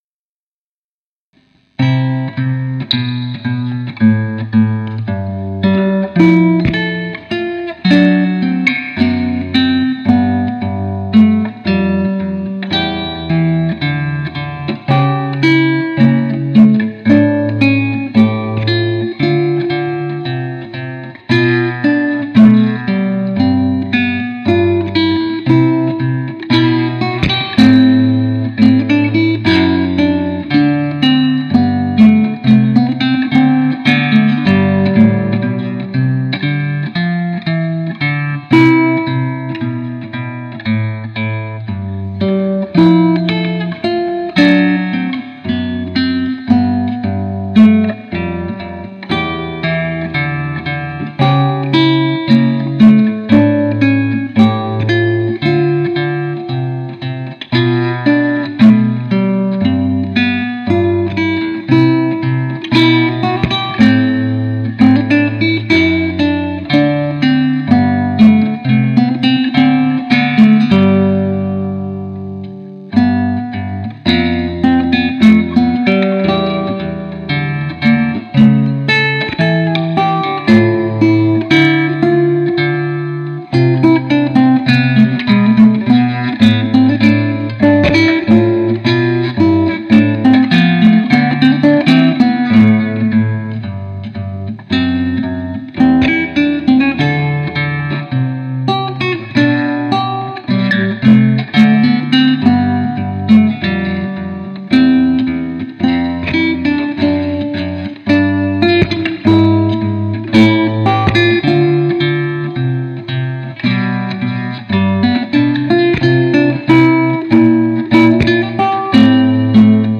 mit einer besseren Aufnahmetechnik aufgenommen!!!